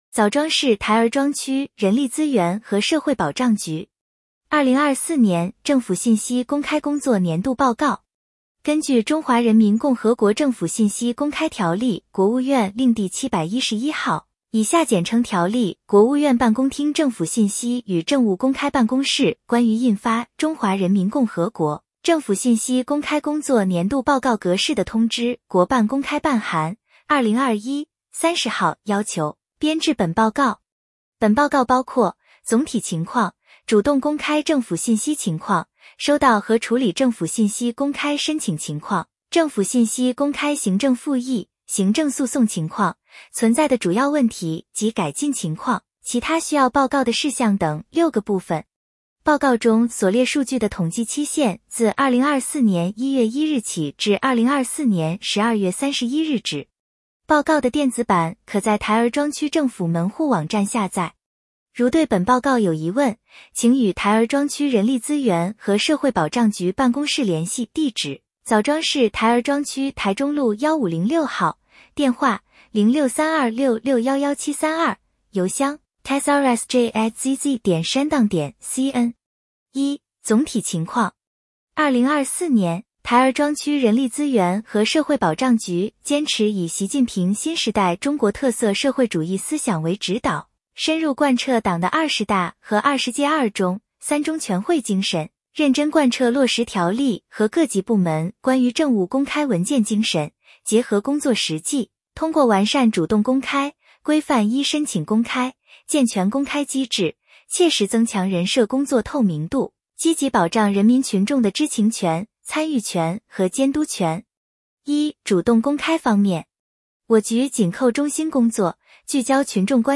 点击接收年报语音朗读 枣庄市台儿庄区人力资源和社会保障局2024年政府信息公开工作年度报告 作者： 来自： 时间：2025-01-17 根据《中华人民共和国政府信息公开条例》（国务院令第711号，以下简称《条例》）《国务院办公厅政府信息与政务公开办公室关于印发＜中华人民共和国政府信息公开工作年度报告格式＞的通知》（国办公开办函〔2021〕30号）要求，编制本报告。